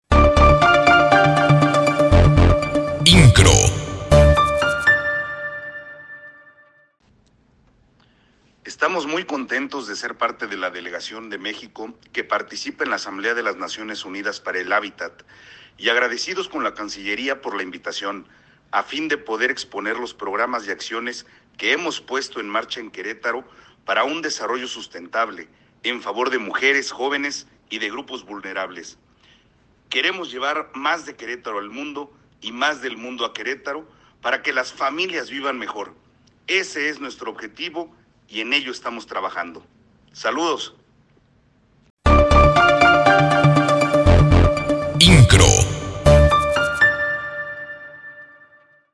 AUDIO-Mensaje del Presidente Municipal desde Nairobi – inqro
AUDIO-Mensaje-del-Presidente-Municipal-desde-Nairobi.m4a